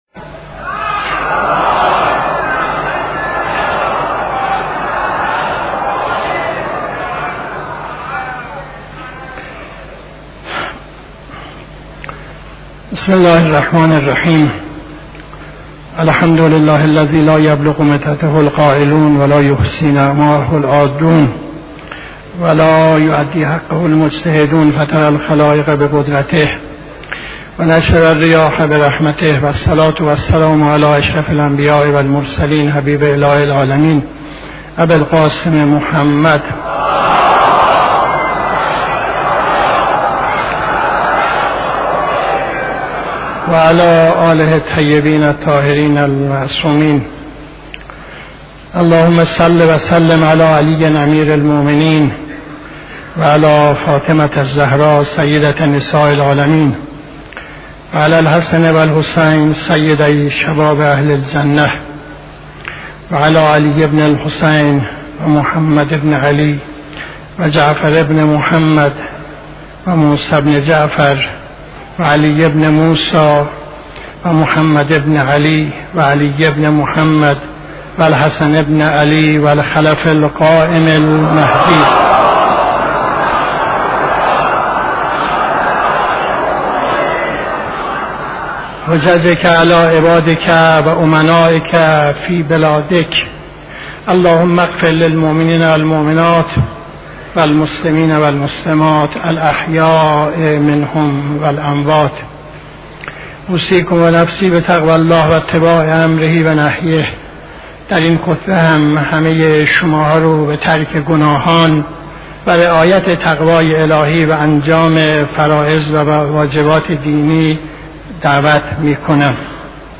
خطبه دوم نماز جمعه 31-06-74